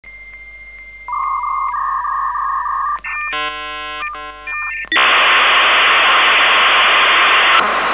Здесь вы можете слушать и скачивать знаменитые сигналы dial-up соединения: от начального гудка до узнаваемых помех.
Звук модема при подключении